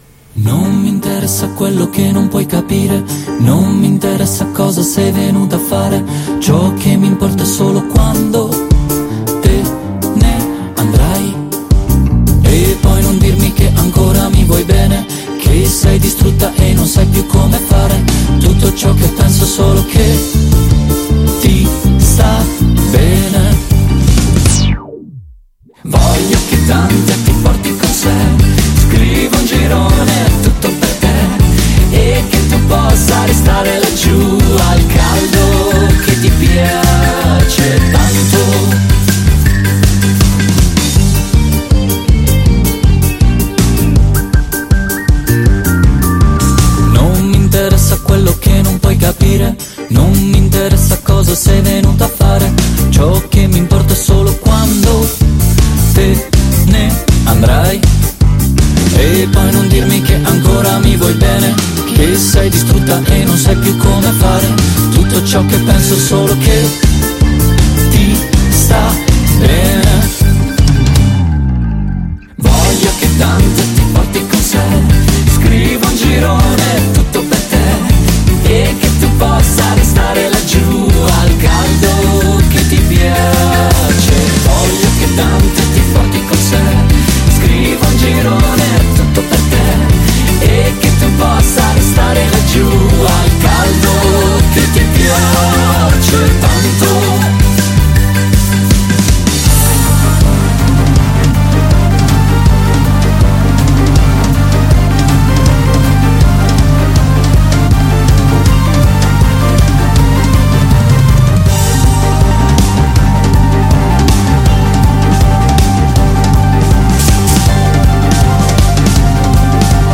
INTERVISTA AI JASPERS | 18-7-22 | Radio Città Aperta